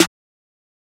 SNARE 8.wav